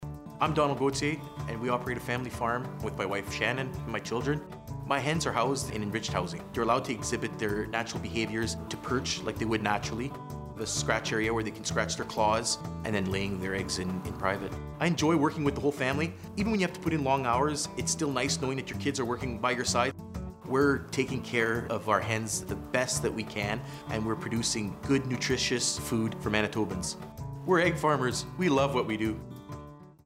Egg farmers demonstrate their love for egg farming in these 30-second radio spots (MP3).